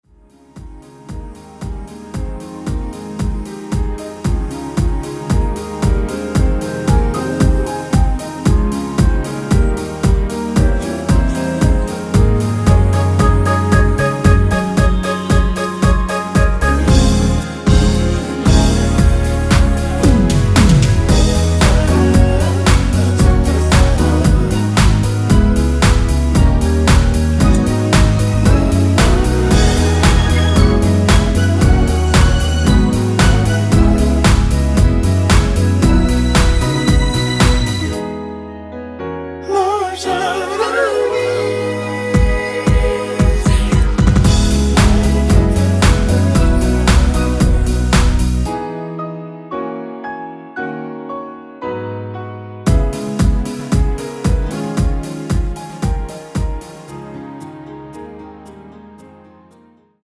원키에서(-2)내린 멜로디와 코러스 포함된 MR입니다.
Ab
앞부분30초, 뒷부분30초씩 편집해서 올려 드리고 있습니다.
중간에 음이 끈어지고 다시 나오는 이유는